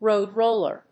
アクセントróad ròller